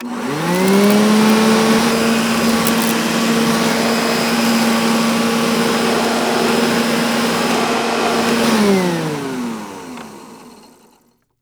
vacuum.wav